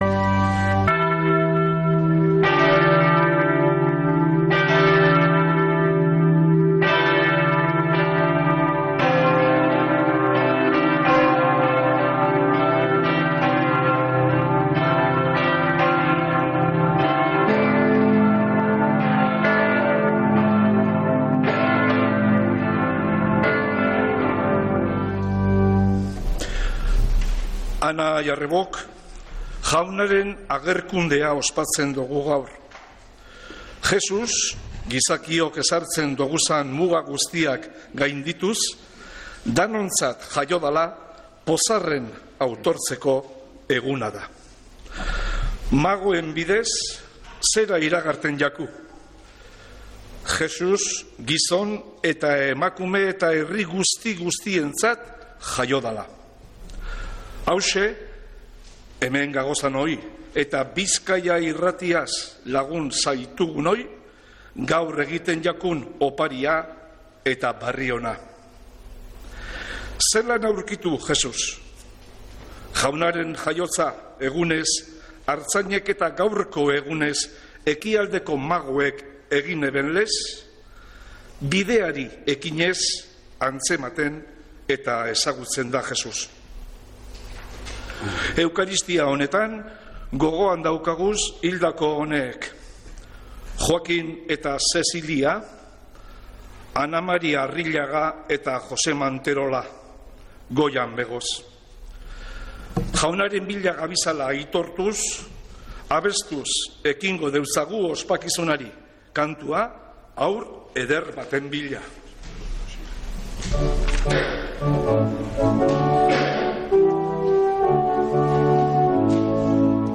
Mezea San Felicisimotik | Bizkaia Irratia